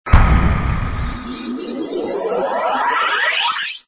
spin.mp3